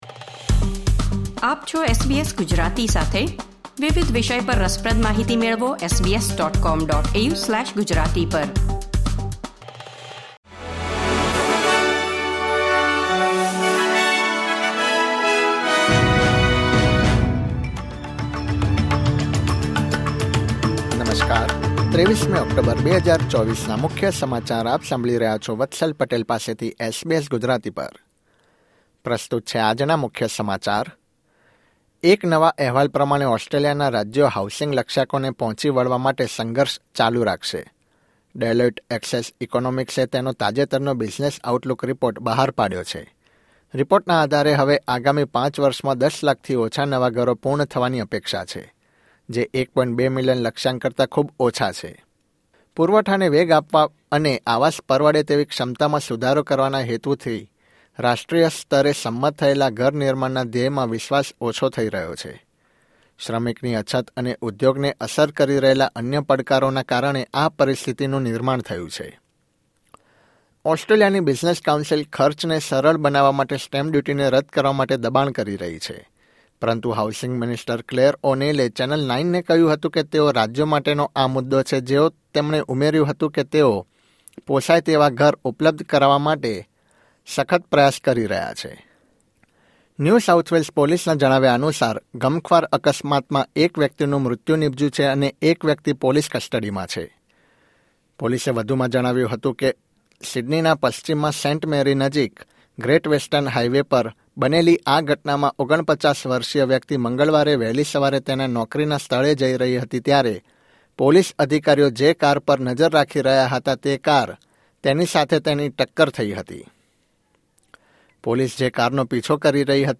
SBS Gujarati News Bulletin 23 October 2024